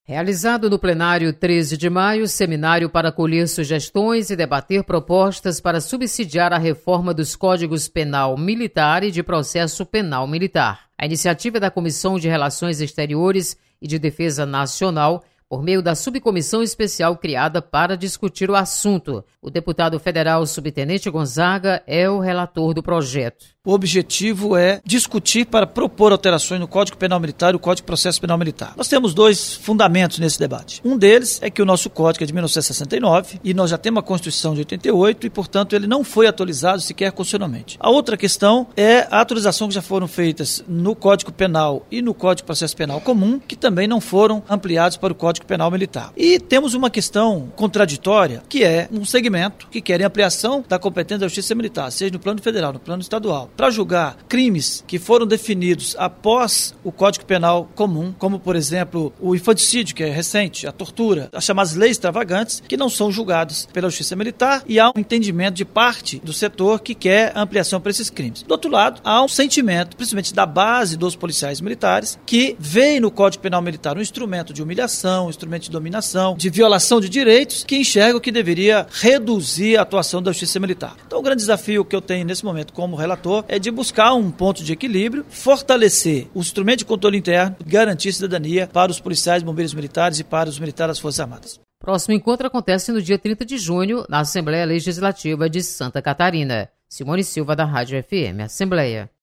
Você está aqui: Início Comunicação Rádio FM Assembleia Notícias Código